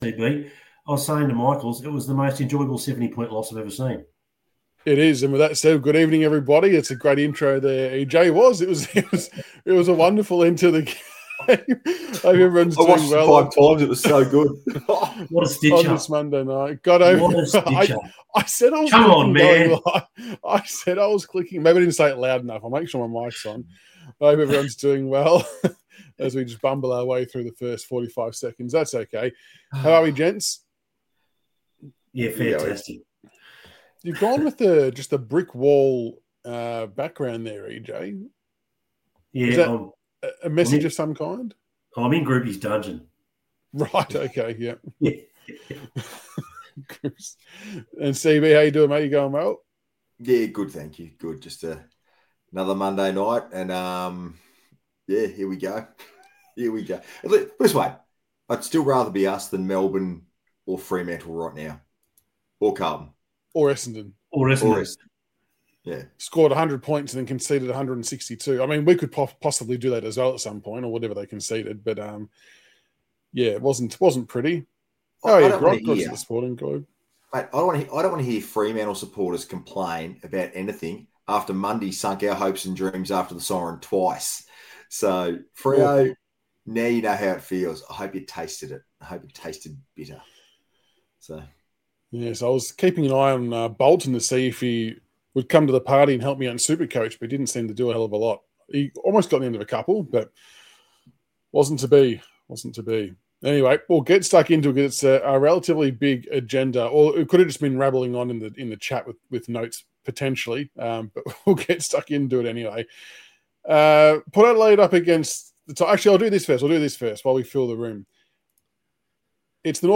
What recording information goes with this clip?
Round3_vs_stkilda_liveshow.mp3